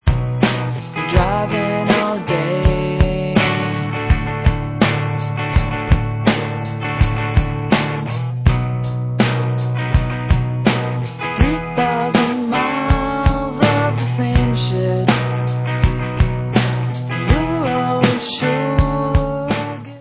sledovat novinky v oddělení Alternativní hudba